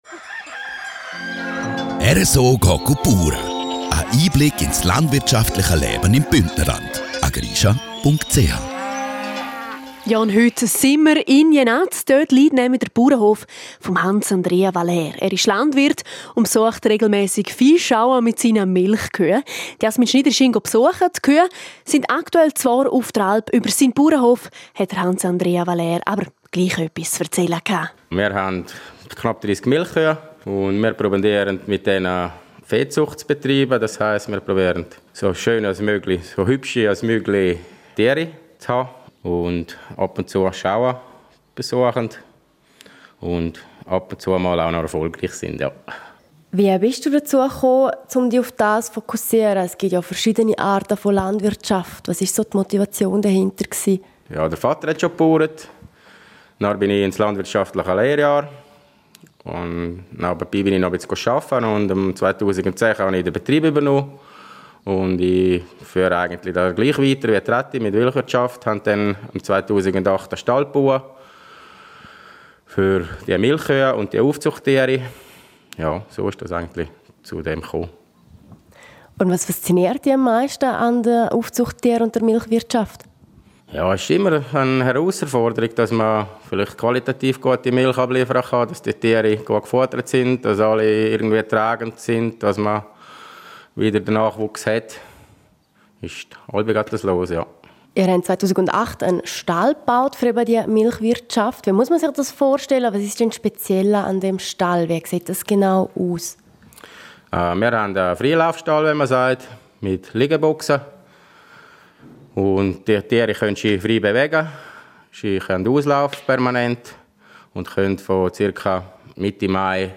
Zu Besuch auf einem Tierzuchtbetrieb in Jenaz